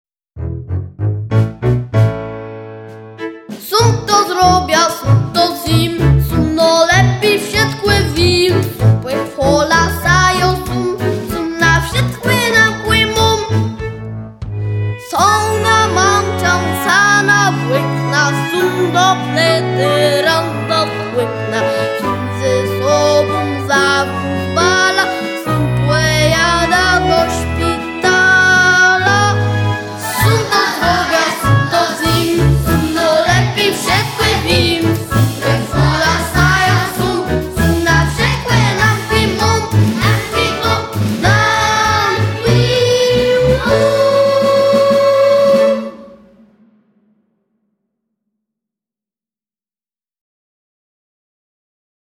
Nagranie wykonania utworu tytuł: Tómk- sómk , autor: Różni Wykonawcy Odsłuchań/Pobrań 3